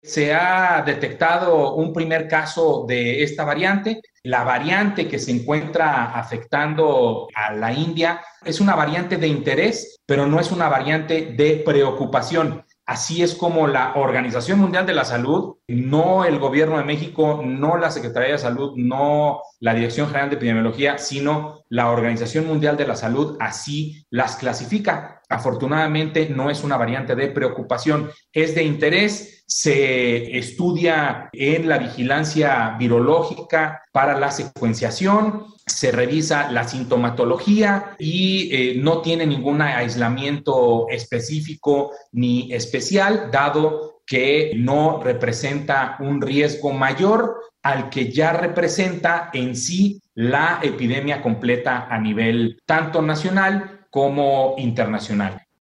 Durante la conferencia vespertina, Ricardo Cortés Alcalá, director general de Promoción de la Salud, aseguró que fue la OMS quien catalogó a la cepa India como una “variante de interés, pero no de preocupación”.